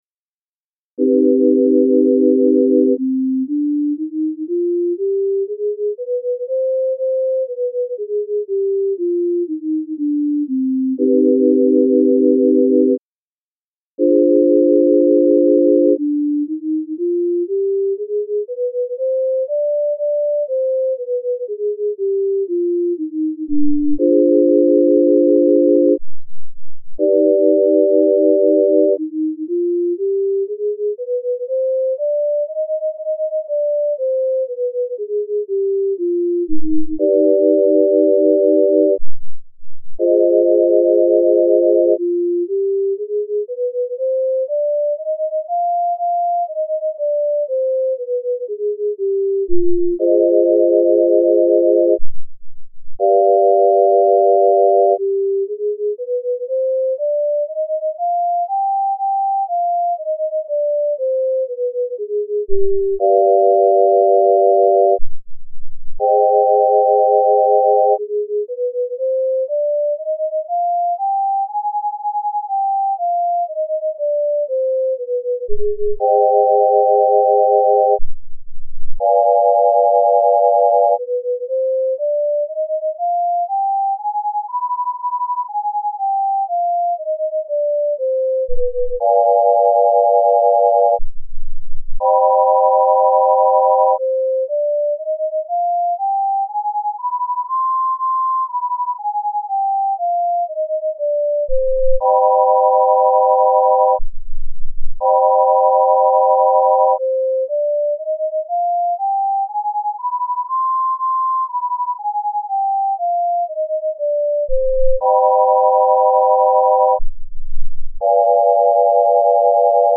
C-Harmonic Minor Scale - Left Ear Pythagorean - Right Ear Just
music02_013_Harmonic_Minor_Pythag_Just.mp3